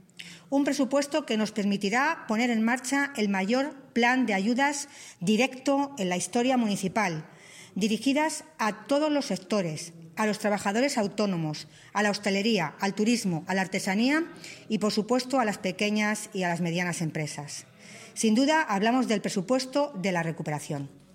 AUDIOS. Milagros Tolón, alcaldesa de Toledo
milagros-tolon_plan-de-ayudas-directas_presupuesto-de-la-recuperacion.mp3